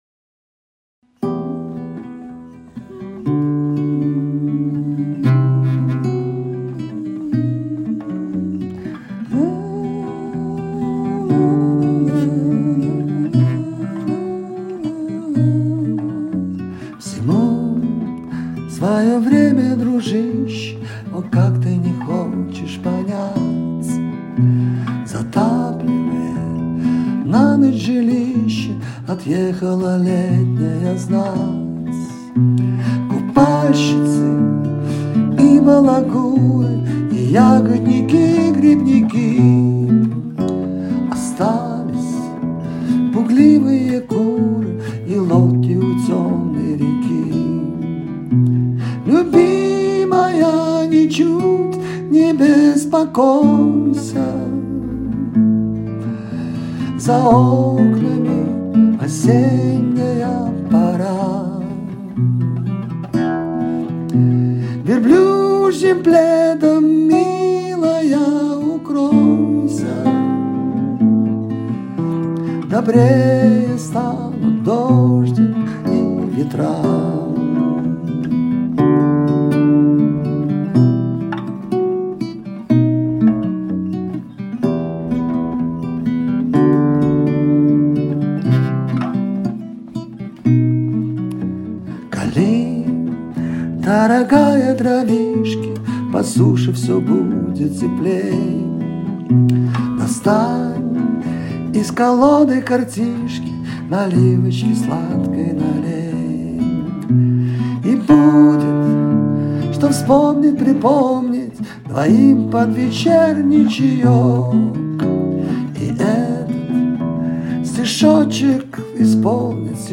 рояль